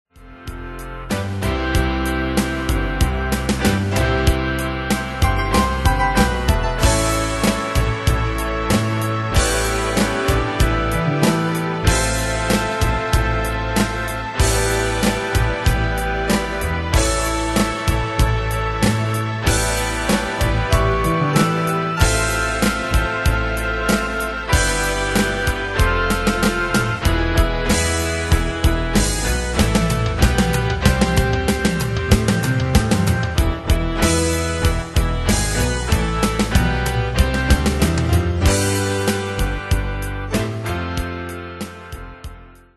Style: Rock Année/Year: 1973 Tempo: 94 Durée/Time: 4.07
Pro Backing Tracks